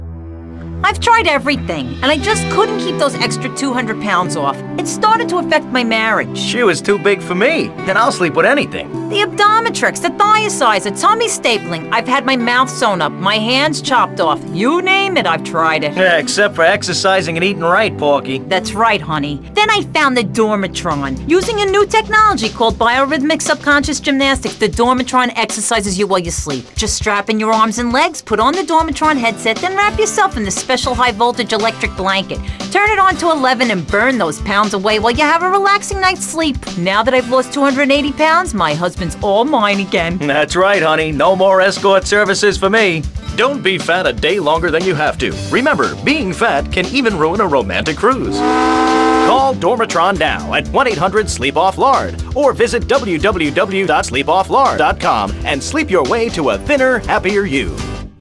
[[Category:Audio ads]]